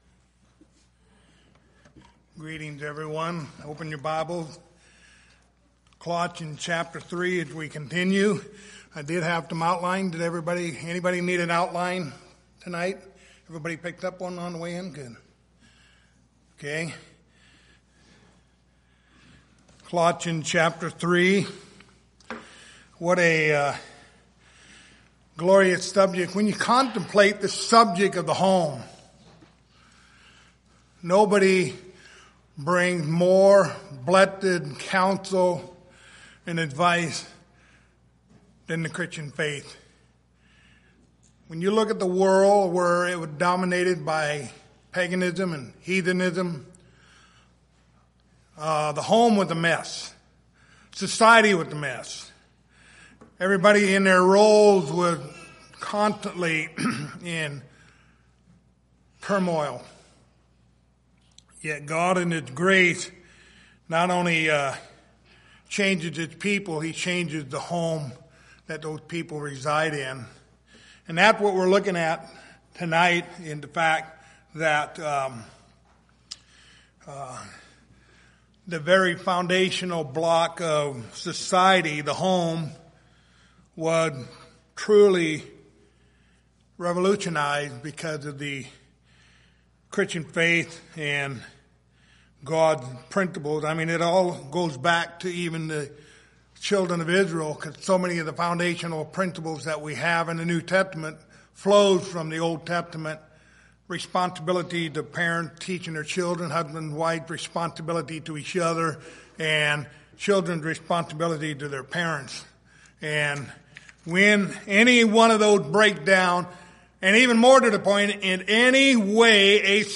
Passage: Colossians 3:18-21 Service Type: Sunday Evening